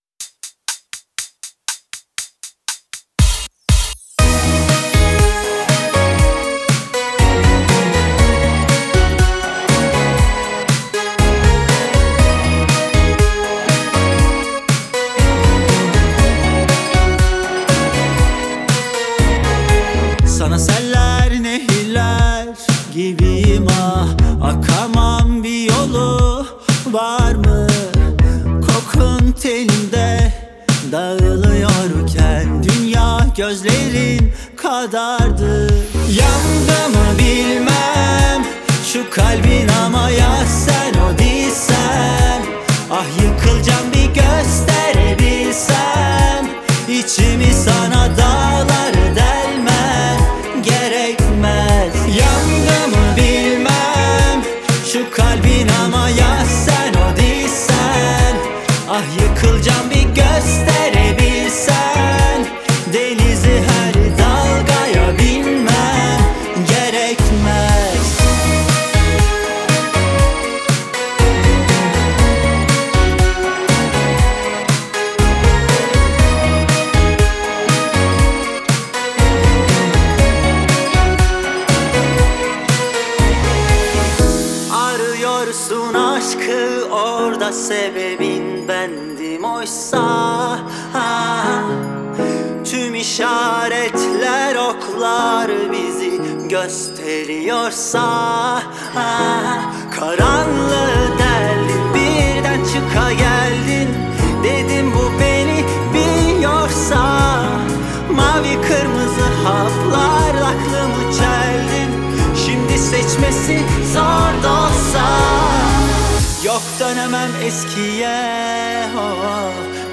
آهنگ ترکیه ای آهنگ غمگین ترکیه ای آهنگ هیت ترکیه ای